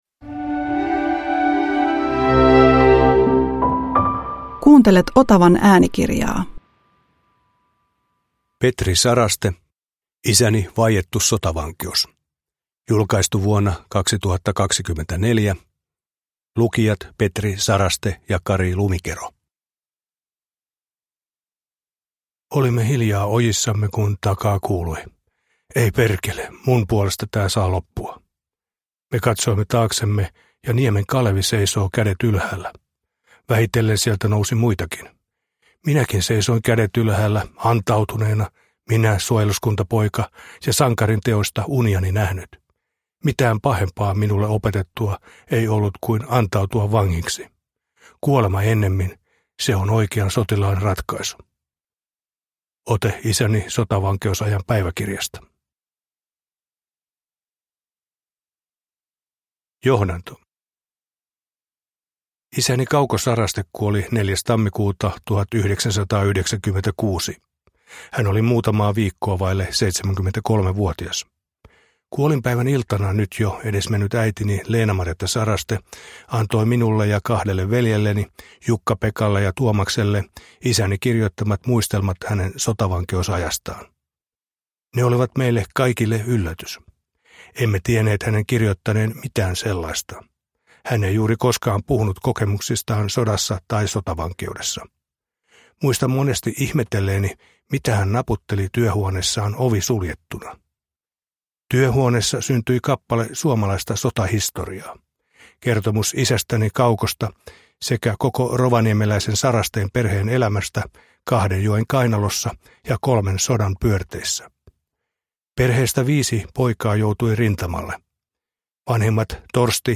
Isäni vaiettu sotavankeus – Ljudbok